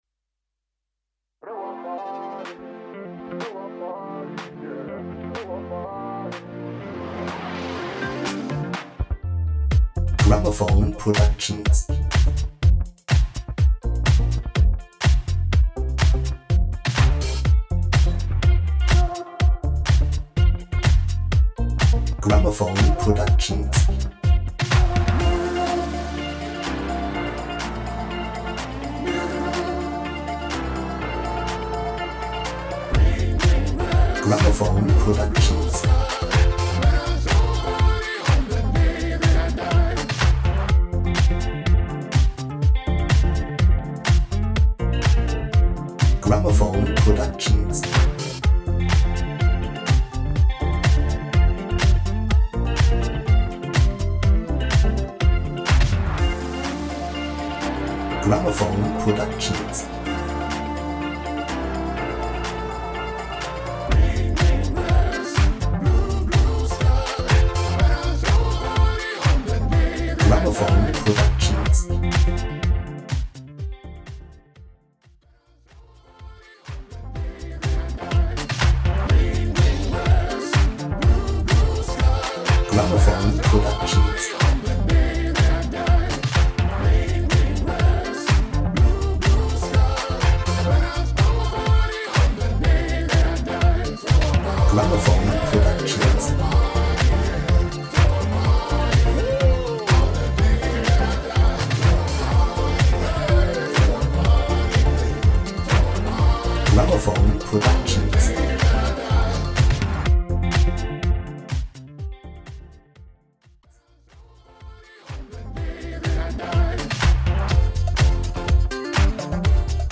Singing Call
INSTRUMENTAL